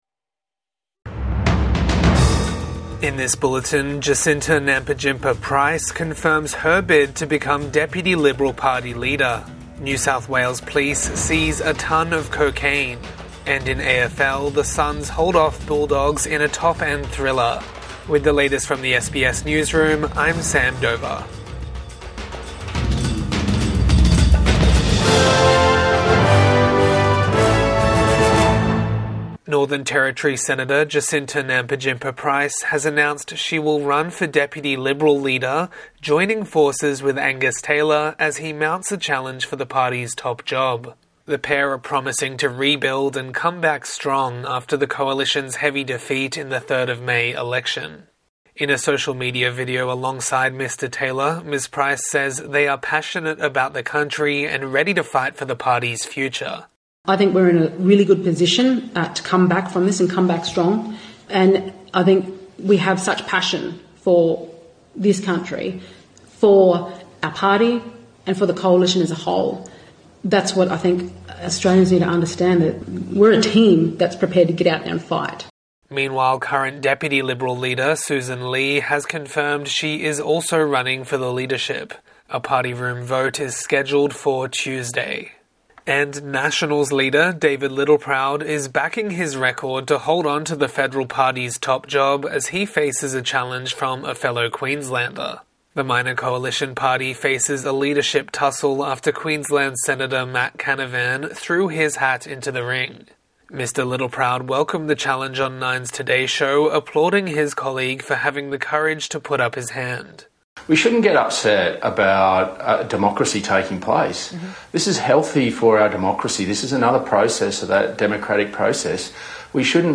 Jacinta Price joins Angus Taylor's bid for Liberal leadership | Midday News Bulletin 11 May 2025